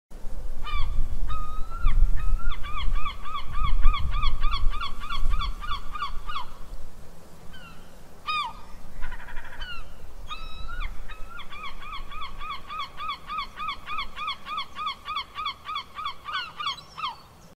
Download Seagull sound effect for free.
Seagull